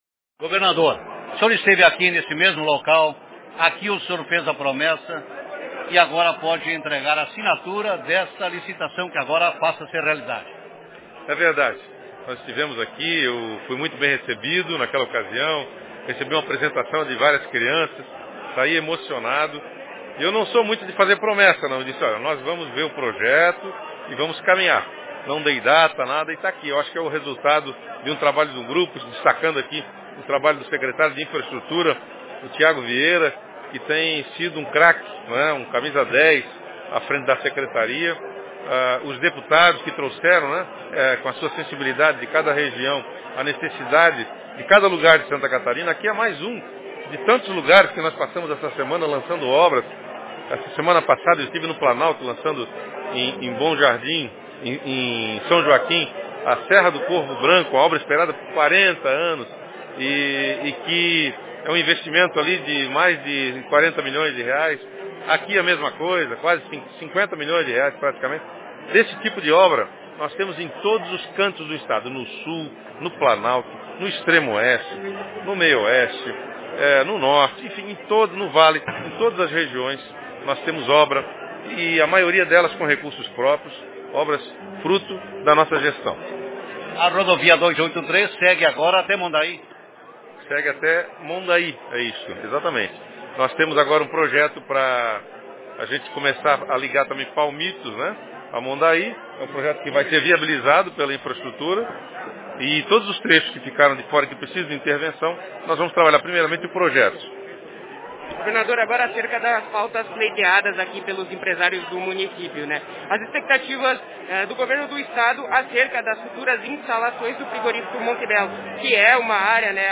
Pronunciamento do prefeito de Palmitos, Dair Jocely Enge
Pronunciamento do governador Carlos Moisés